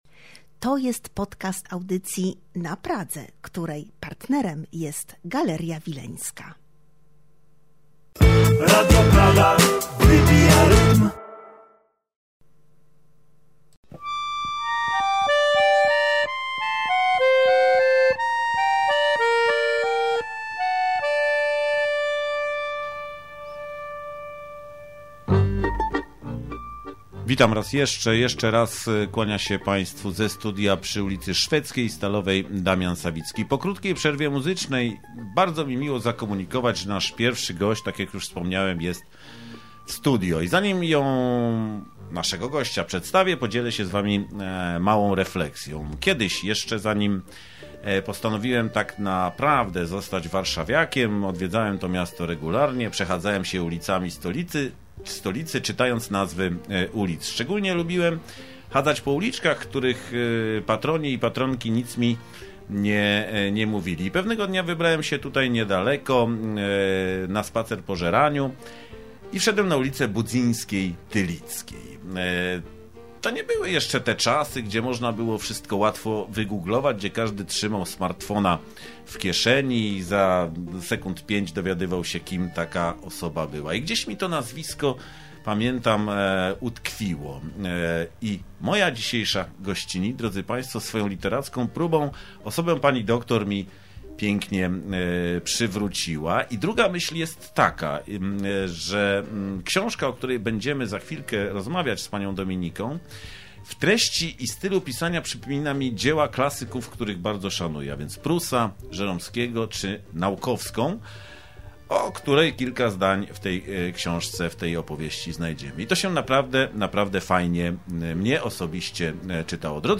Z moją gościnią rozmawiałem o początkach polskiego feminizmu, pomyśle na książkę, praskich odniesieniach i przyszłości.